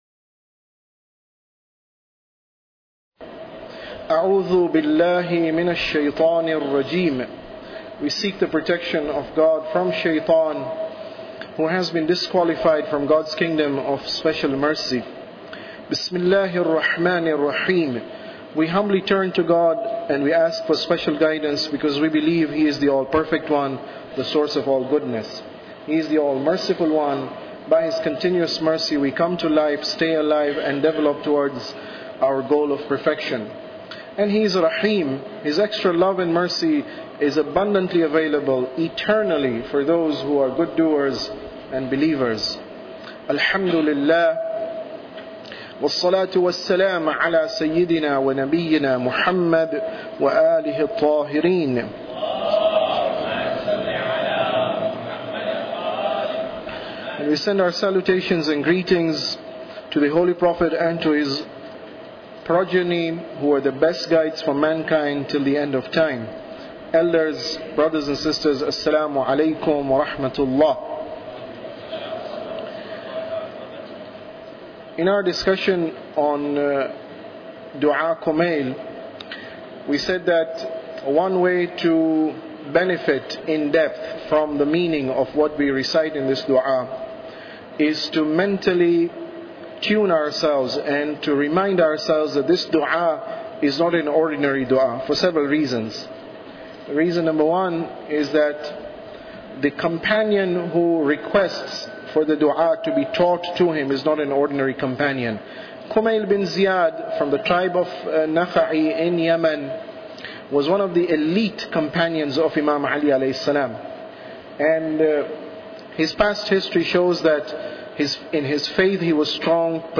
Tafsir Dua Kumail Lecture 14